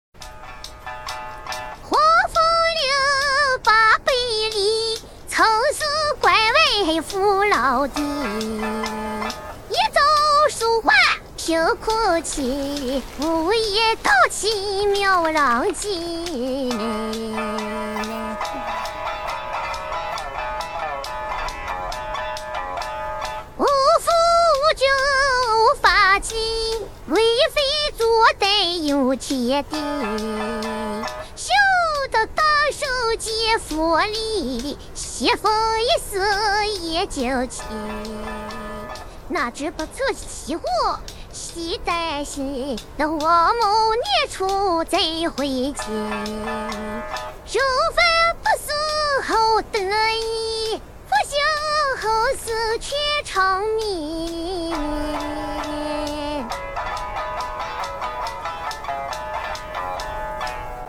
所以这几天放假就给大家制作了一个萝莉音色的诗歌剧模型。
唱歌部分